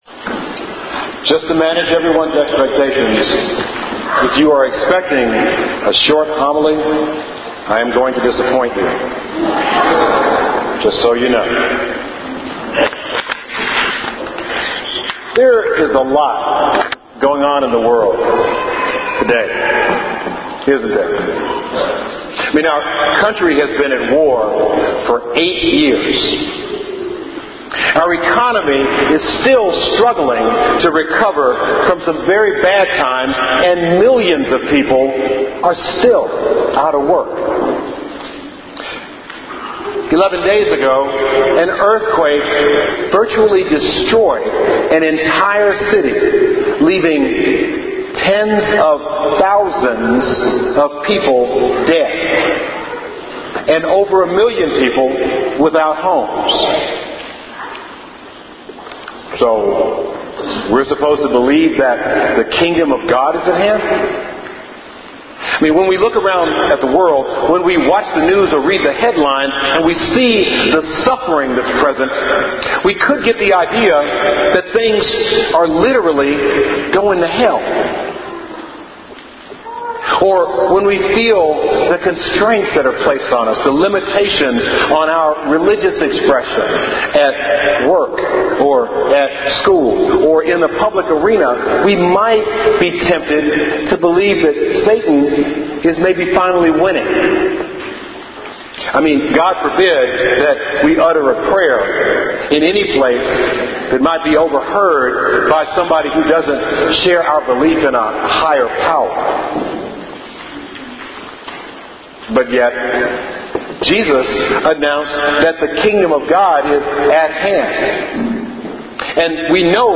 Homily – Respect Life Sunday 2010 (January 24, 2010)
homily-ot-3c-the-kingdom-of-god-is-at-hand.mp3